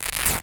rip2.ogg